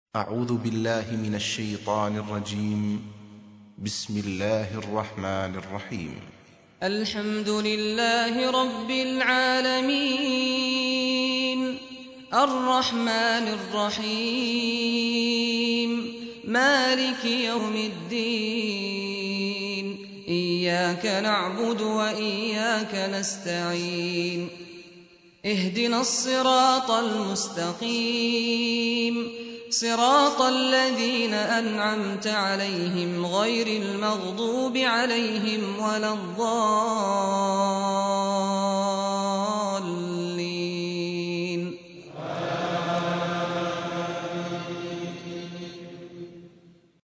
سورة الفاتحة | القارئ سعد الغامدي